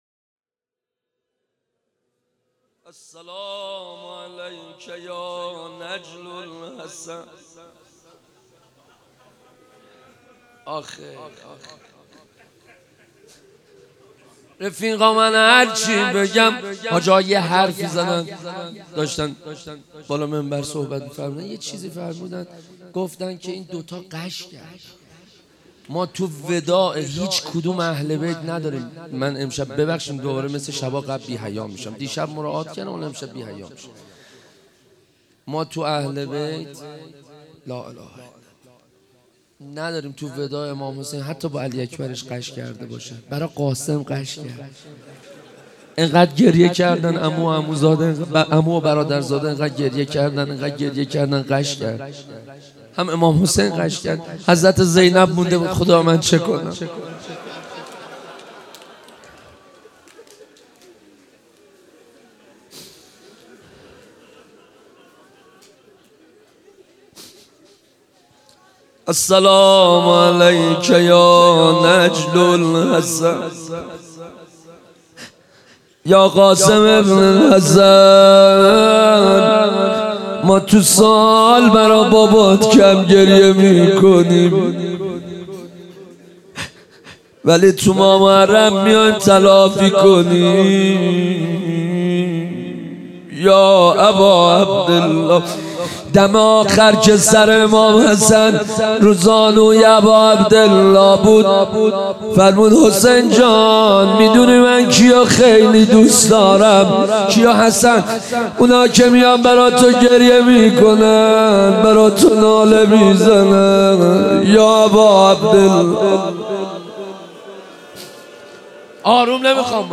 روضه حضرت قاسم